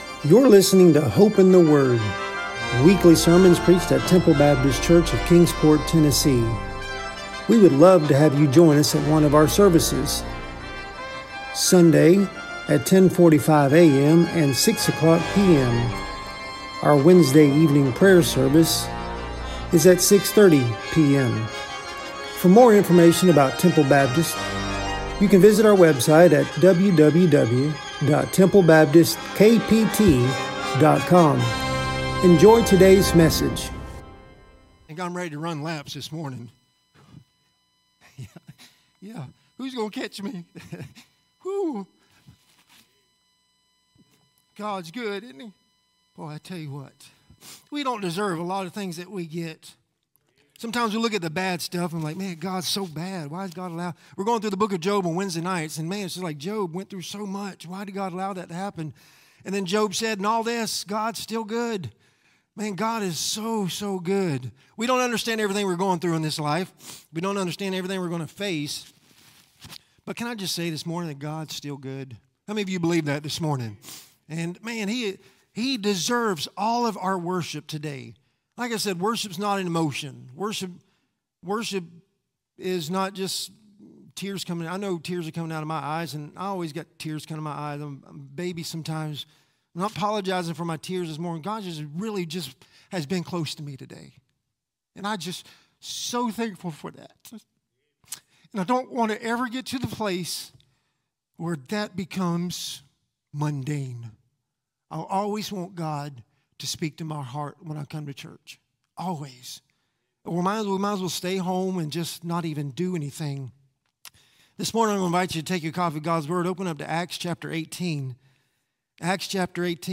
Sermons | Temple Baptist Church